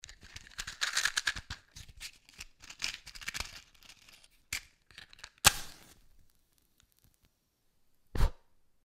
Звуки спички
Взяли спичку с коробка зажгли и потушили